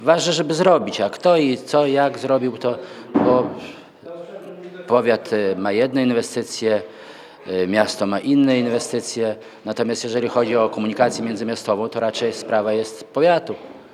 Jak mówi Romuald Witkowski, wicestarosta sejneński, z dworca korzystają także mieszkańcy powiatu.